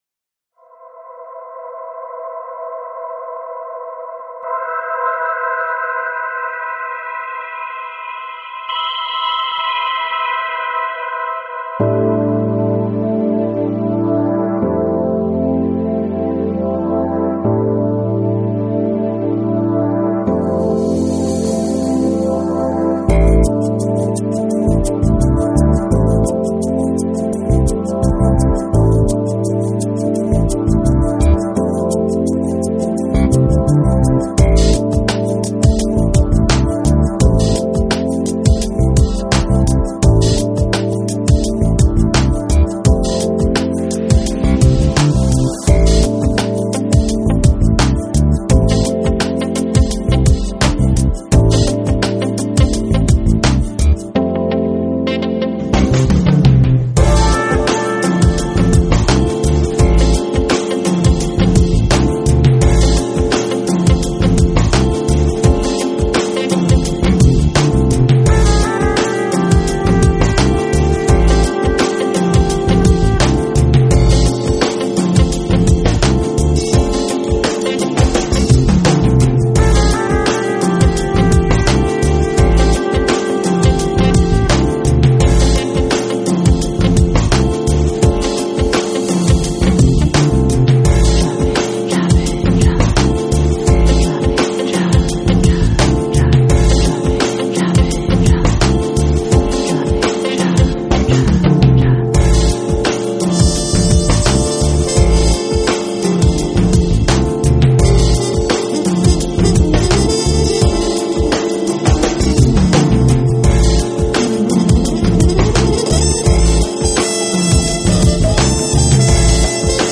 Genre: Lo-Fi / Deep House | Soulfull House